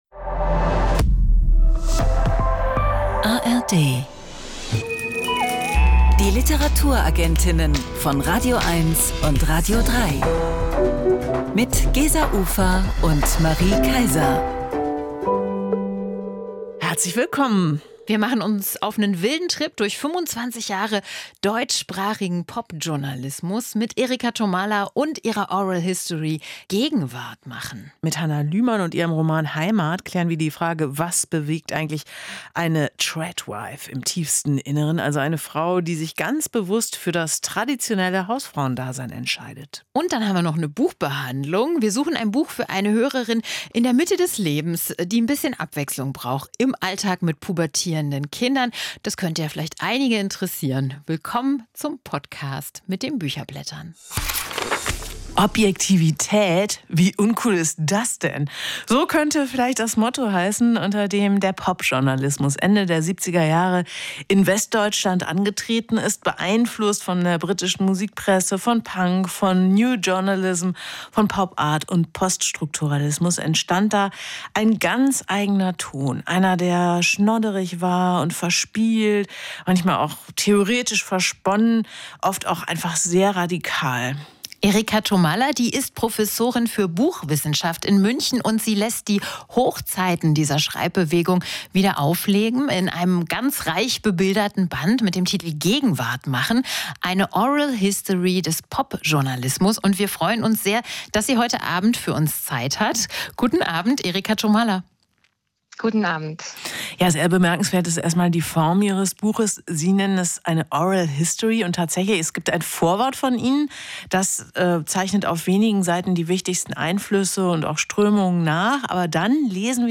Kurz: Eine Literatursendung, die Sie kennen sollten.